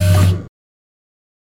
Electric Door Servo End